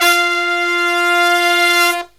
LONG HIT06-R.wav